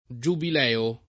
giubileo [ J ubil $ o ]